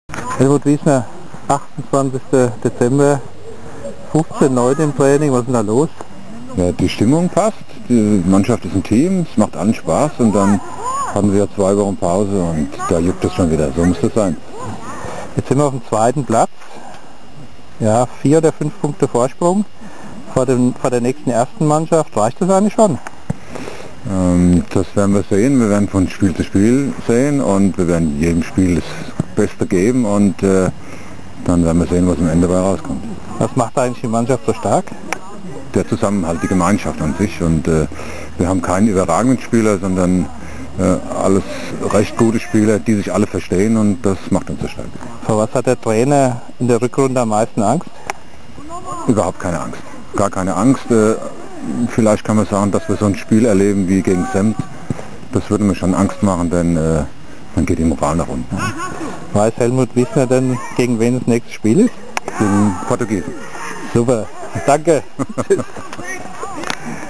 Während einer recht ungewöhnlichen Trainingseinheit am Jahresende ergab sich die Gelegenheit zu einem kurzen Fazit zum bisherigen Verlauf dieser Saison.
Interview